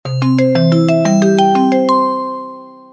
ring.wav